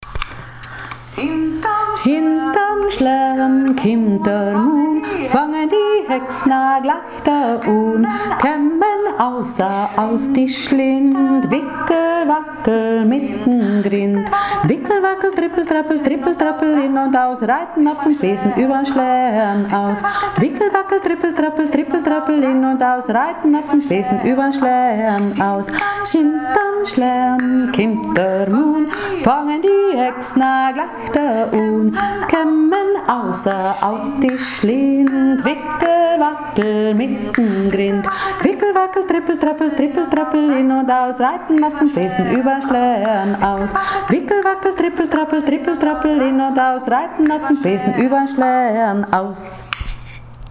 Jodeln und Wandern im Reich der Fanes
Hintern Schlern im Kanon